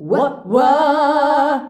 UAH-UAAH D.wav